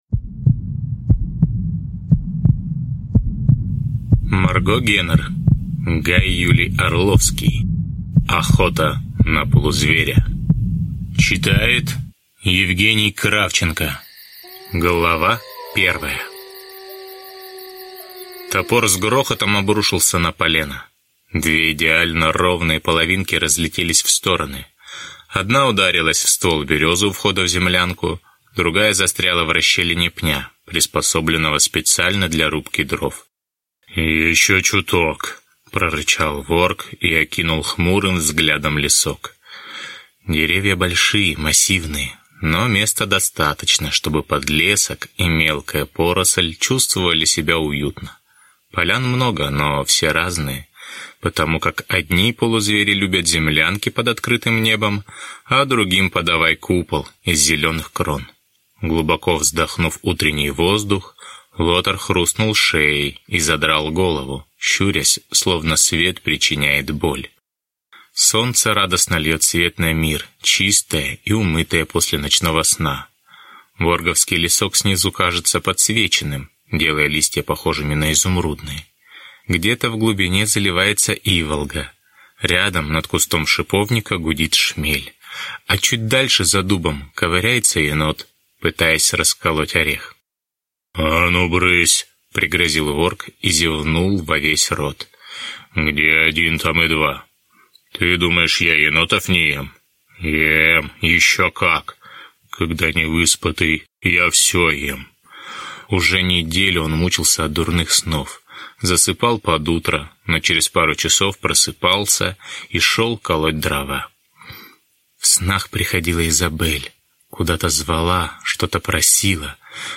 Аудиокнига Охота на полузверя | Библиотека аудиокниг